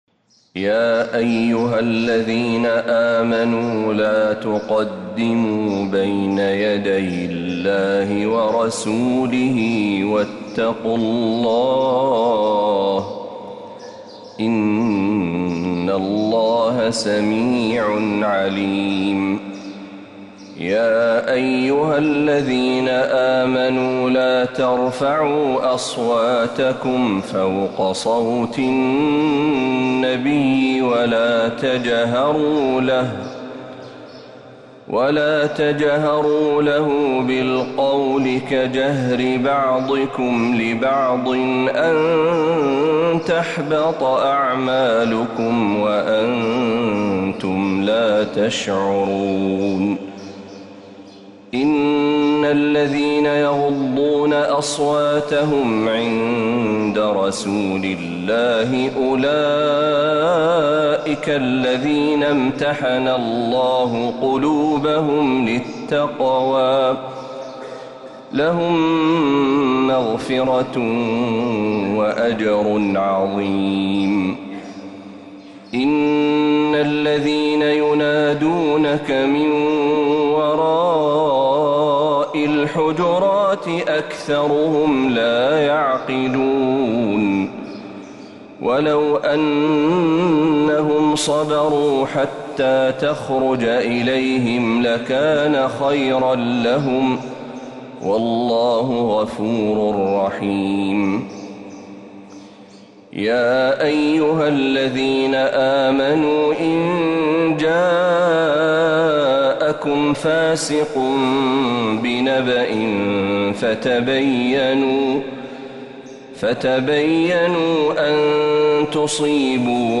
سورة الحجرات كاملة من الحرم النبوي